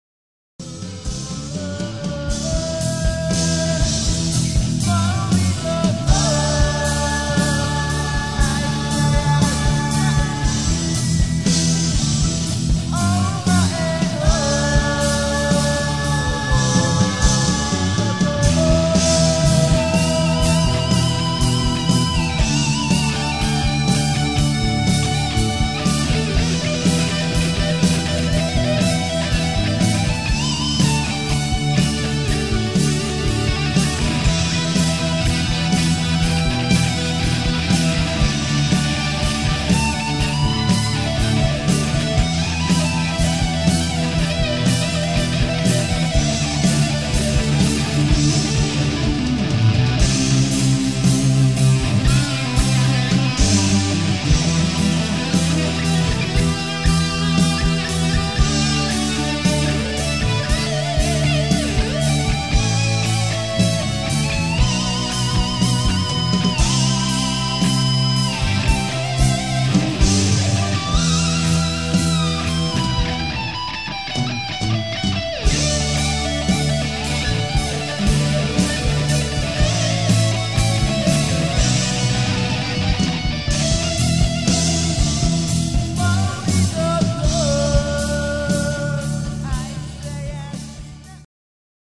後半のギターソロと変則的なリズムとは躍動感を感じさせる。